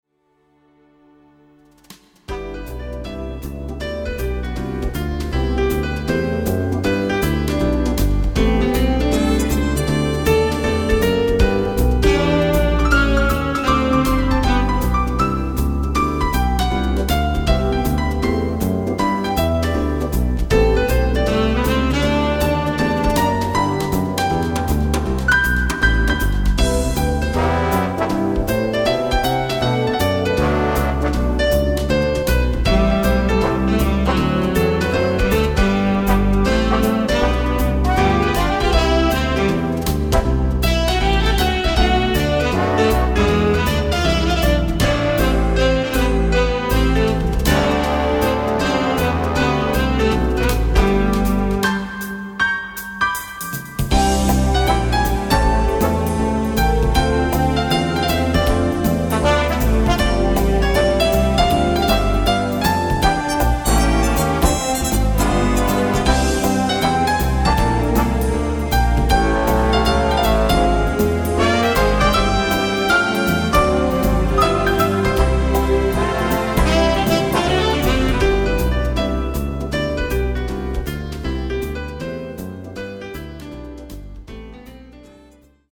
Swing